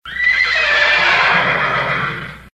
HORSE NEIGHING.mp3
Original creative-commons licensed sounds for DJ's and music producers, recorded with high quality studio microphones.
horse_neighing_a56.ogg